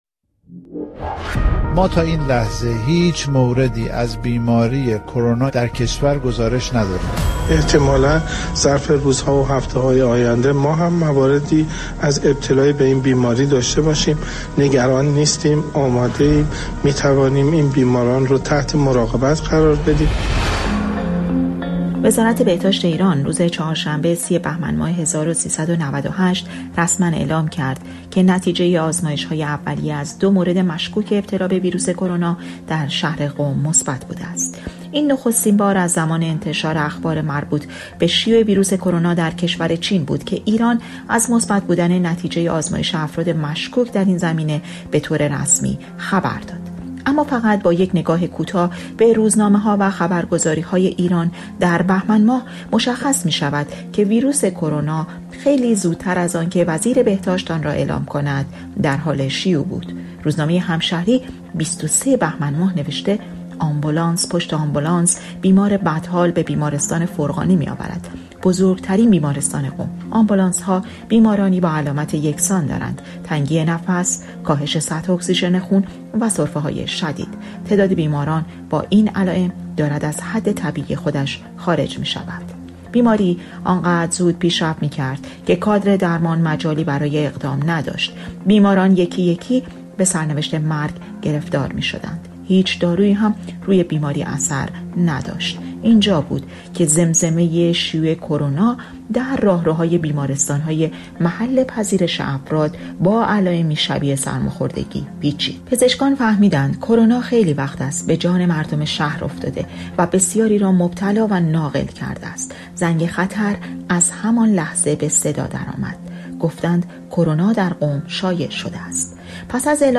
سی‌ام بهمن مصادف است با سالگرد تأیید رسمی شیوع ویروس کرونا در ایران. در گزارشی ویژه، از ورود ویروس کرونا و گسترش آن در ایران، و اقدام‌های جمهوری اسلامی در مقابله با آن می‌شنوید.